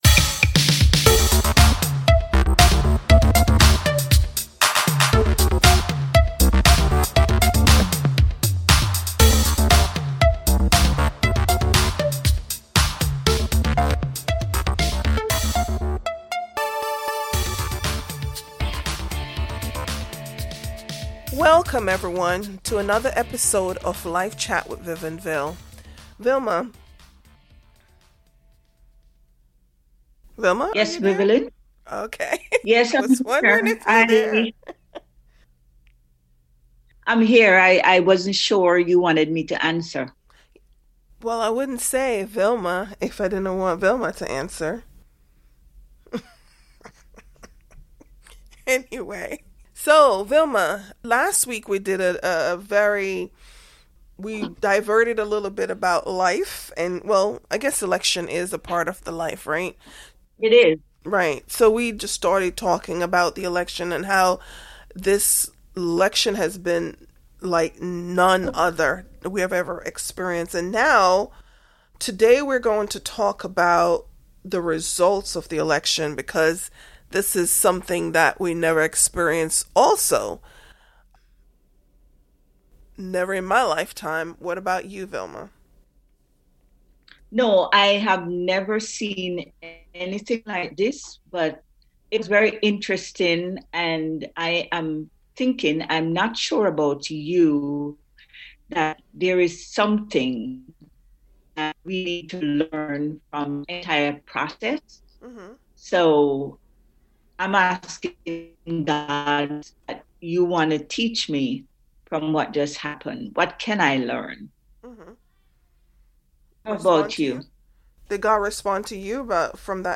The hosts discussing the results of the election and how the church needs to move forward.